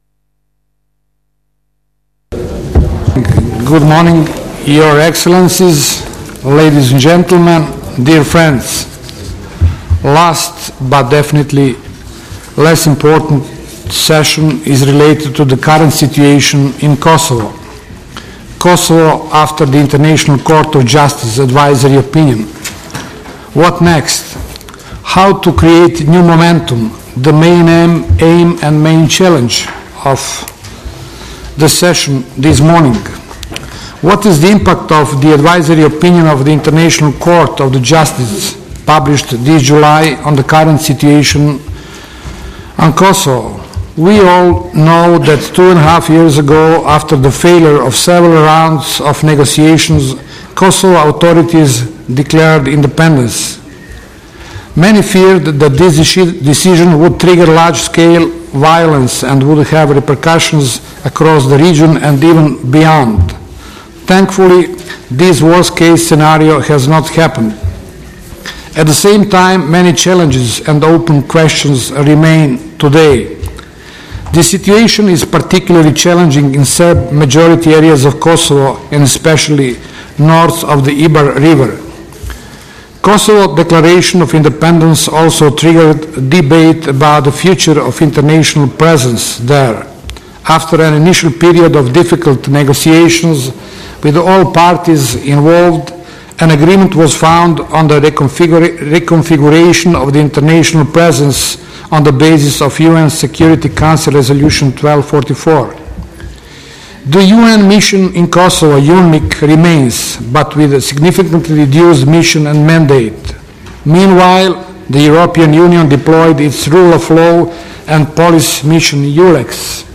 Mr. Krešimir Ćosić, Head of the Croatian Delegation tot he NATO PA, chaired the fifth and final session of the Seminar.